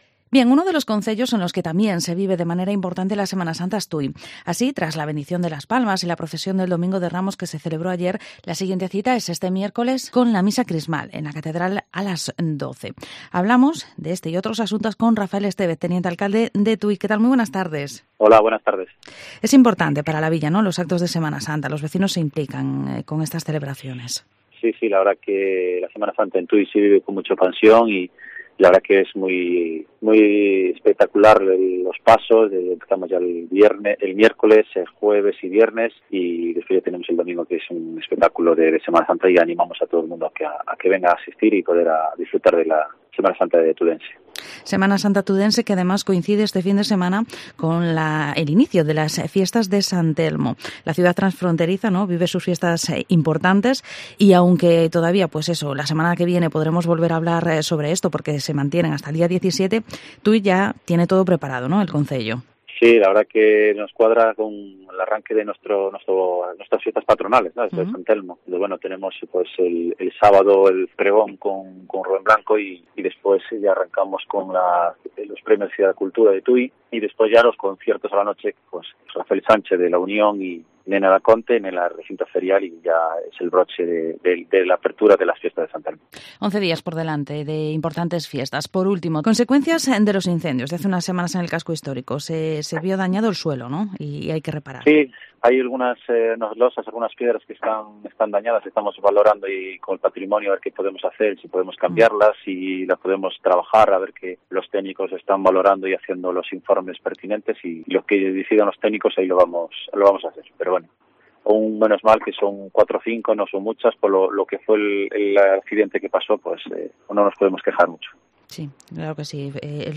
Entrevista a Rafael Estévez, teniente-alcalde de Tui